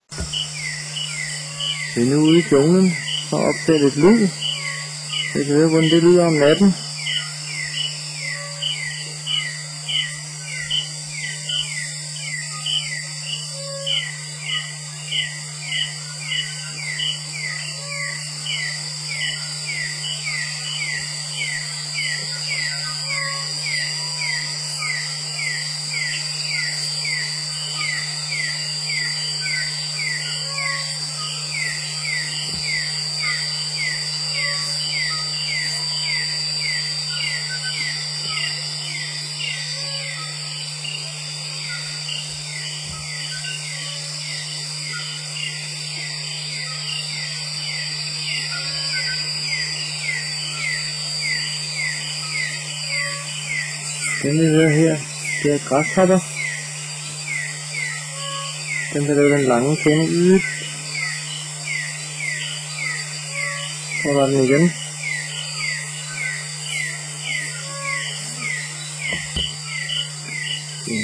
Der er nu stille i Jambo, alle er gået til ro, kun flammernes dansen i ildstedet og lydene fra de mange dyr i junglen er at høre.